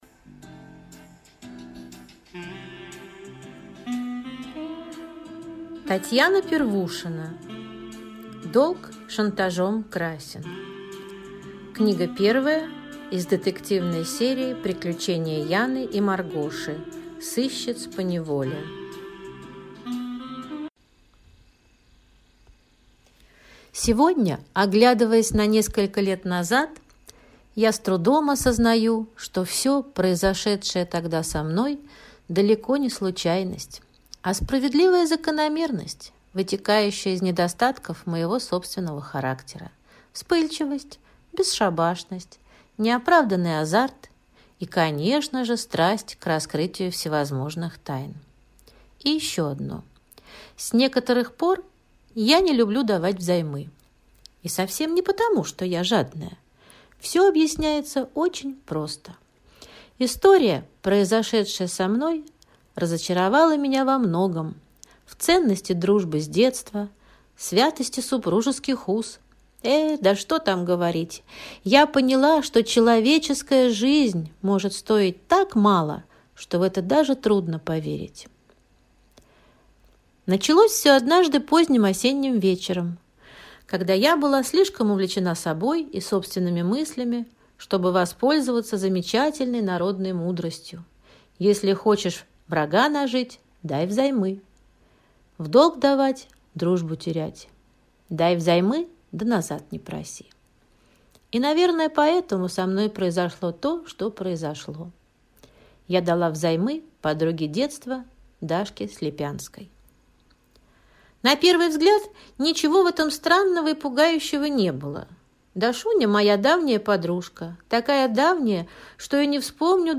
Аудиокнига Долг шантажом красен | Библиотека аудиокниг